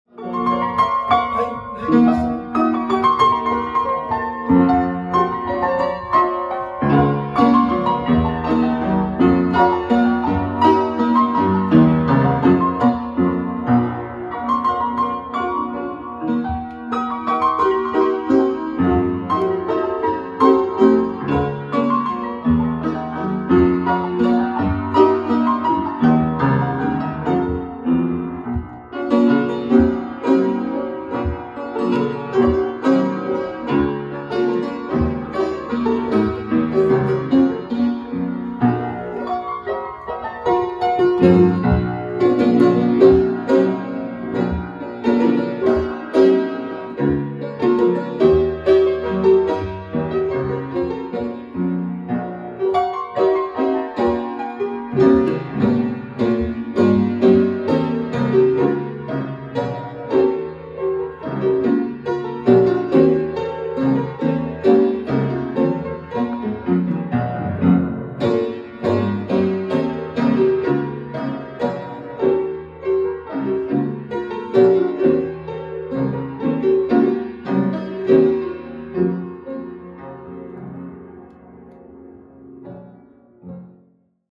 73-ամյա կոմպոզիտորի մատները սահում են դաշնամուրի վրայով այնպես, որ թվում է՝ ստեղներն իրենք իրենց են սեղմվում, և երաժշտությունը տարածվում է անցյալ դարասկզբին կառուցված բնակարանում։